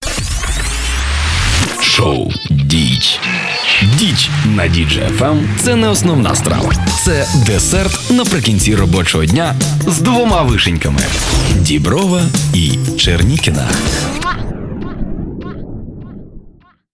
Начитую рекламні ролики.
Теги: Announcer, Audio_Production, Host Of Programs